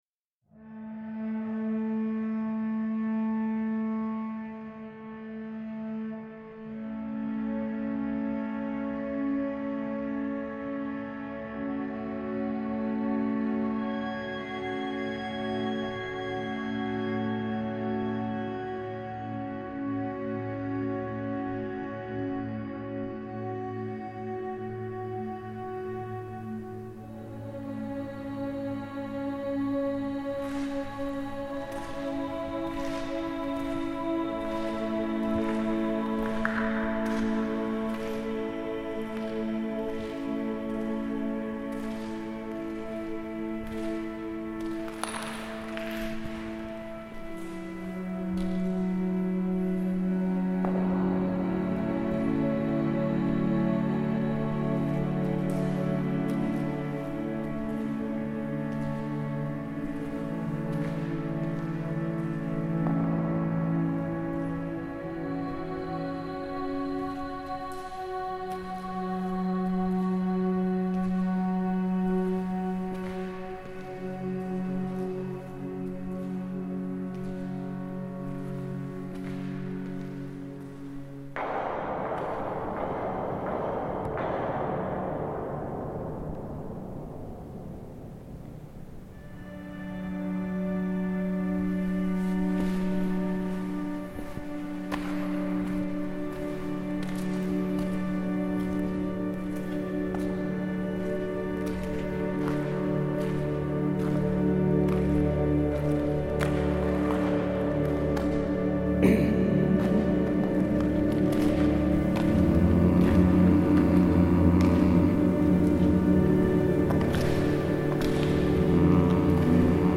Djúpavík oil tank, Iceland